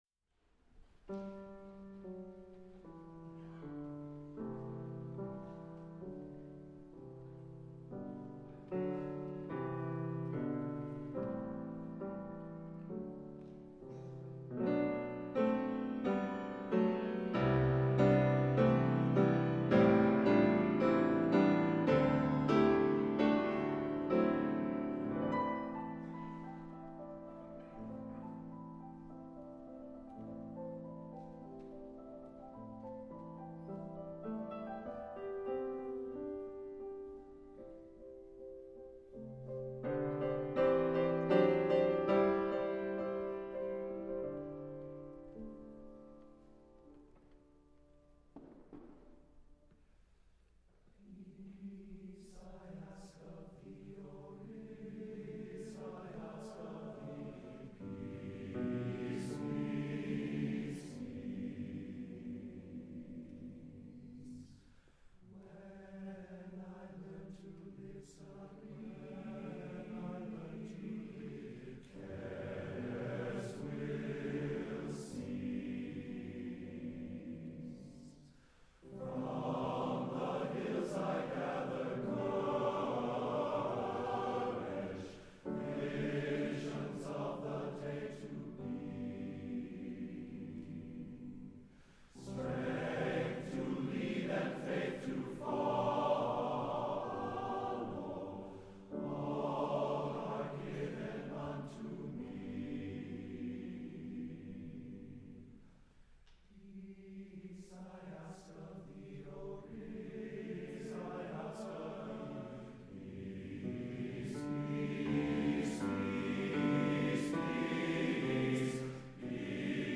(TTBB Chorus [2008] version of mvts. 1 & 2 available)
This is a gentle song.